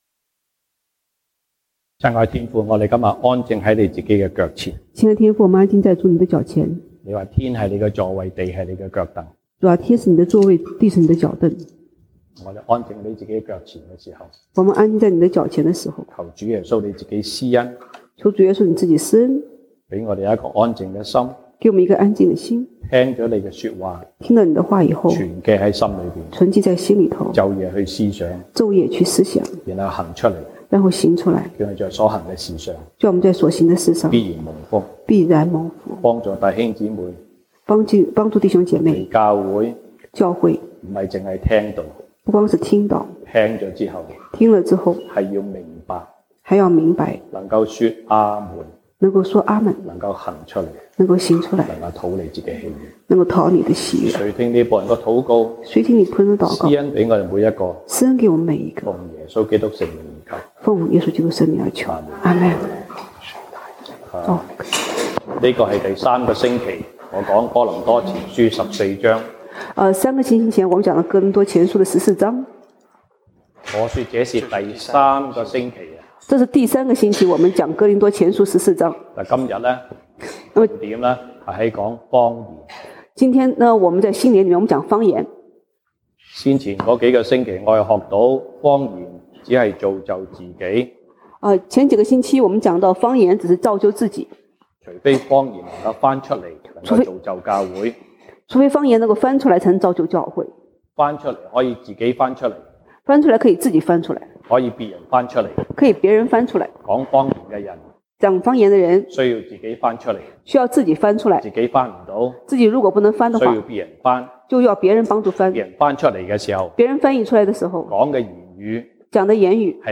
西堂證道(粵語/國語) Sunday Service Chinese: 新的開始
Passage: 歌林多前書 1 Corinthians 14:1-40 Service Type: 西堂證道(粵語/國語) Sunday Service Chinese